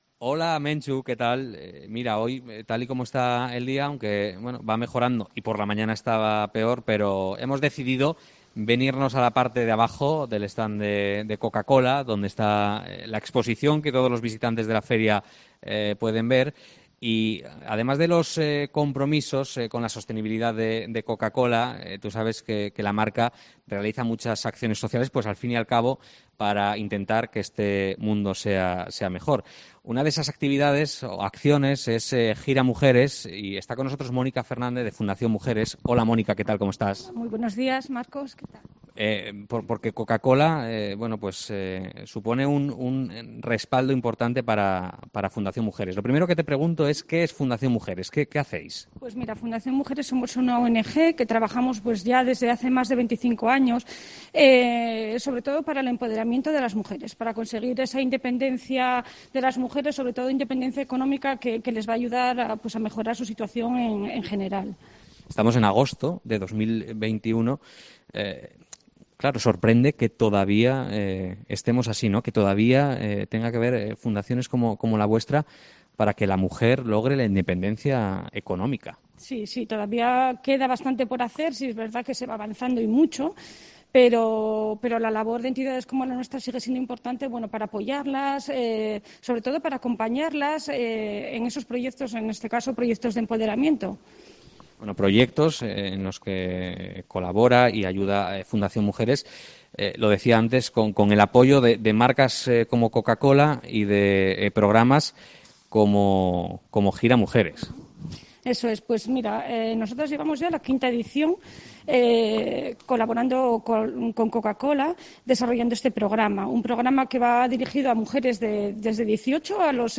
Entrevista
en el stand de Coca-Cola en la FIDMA